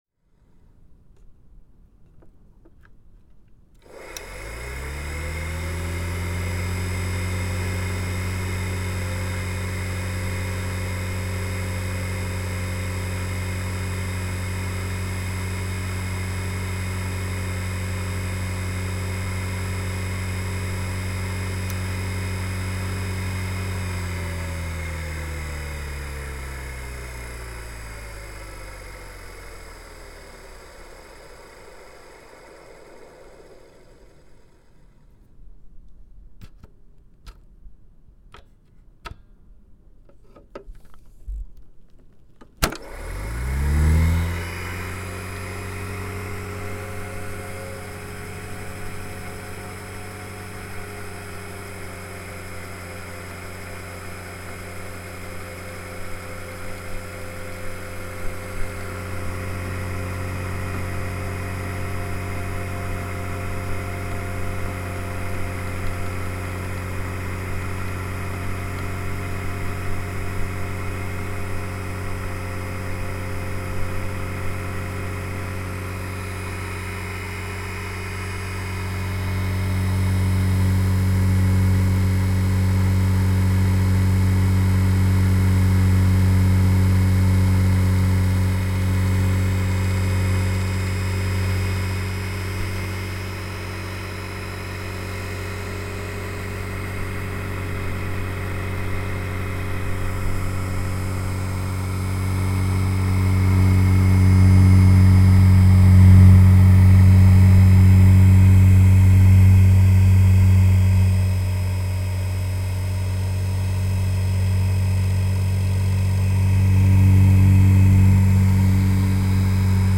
This is a close mic recording of the fan/motor on the Sony AV3400 Porta Pak circa 1969.